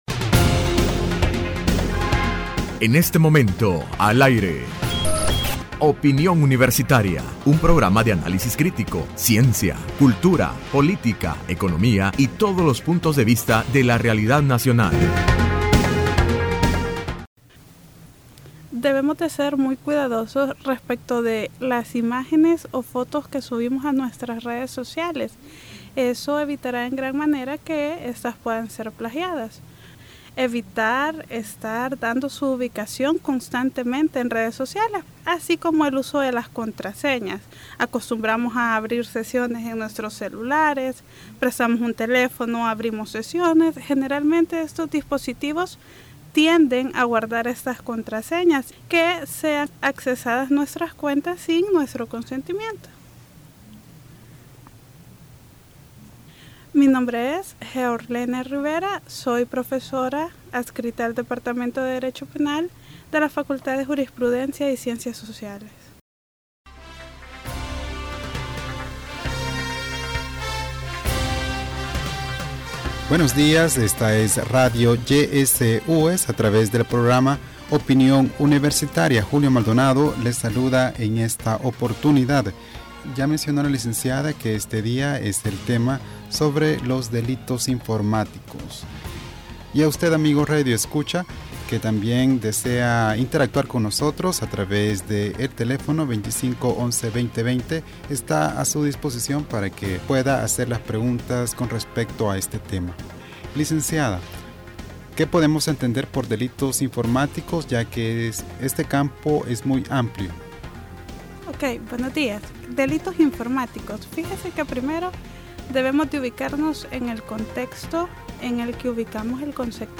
Entrevista Opinión Universitaria(27 de octubre 2015): Delitos Informáticos en El Salvador.